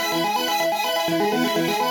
Index of /musicradar/shimmer-and-sparkle-samples/125bpm
SaS_Arp02_125-E.wav